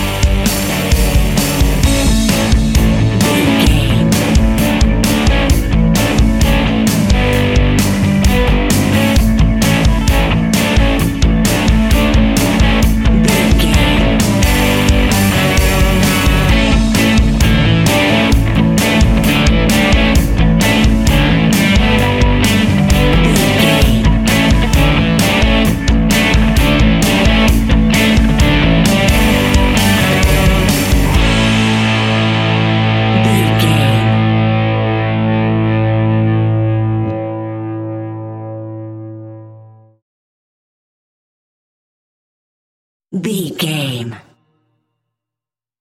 Ionian/Major
energetic
driving
heavy
aggressive
electric guitar
bass guitar
drums
hard rock
heavy metal
blues rock
distortion
instrumentals
distorted guitars
hammond organ